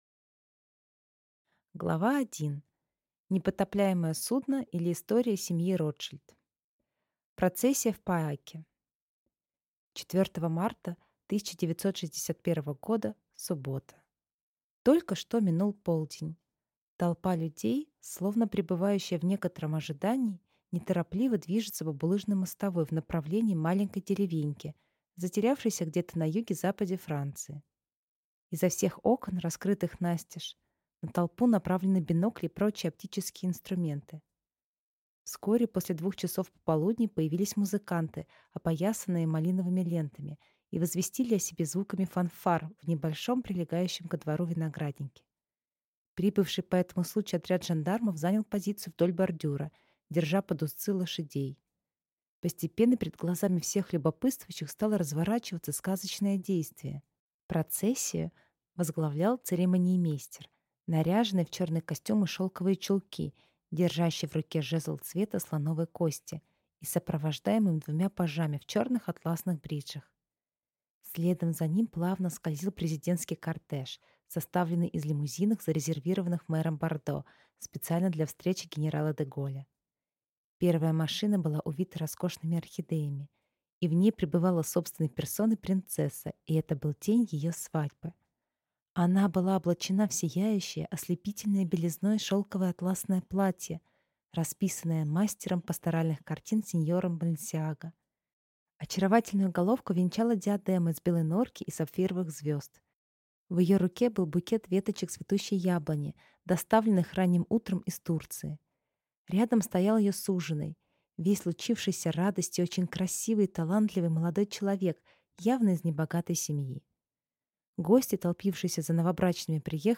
Аудиокнига Ротшильды. История династии могущественных финансистов | Библиотека аудиокниг